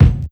KICK_BUDDAH.wav